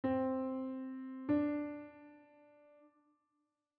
Melodic ascending (bottom note played, then top note played), melodic descending (top note played, then bottom note played), and harmonic (both notes played at the same time).
Major 2nd
C-Major-Second-Interval-S1.wav